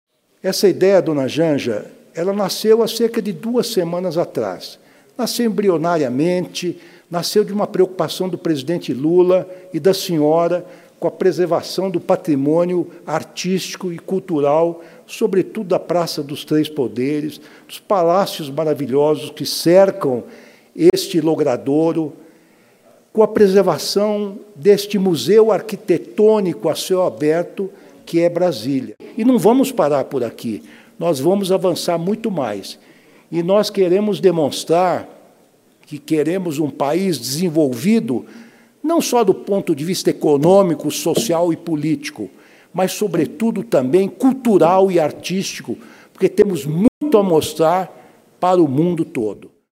Fala do ministro Ricardo Lewandowski no lançamento dos projetos de Restauração do Palácio da Justiça e do Centro de Memória do MJSP.mp3 — Ministério da Justiça e Segurança Pública